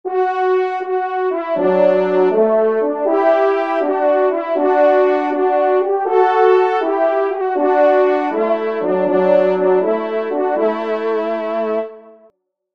Genre : Fantaisie Liturgique pour quatre trompes
Pupitre 2° Trompe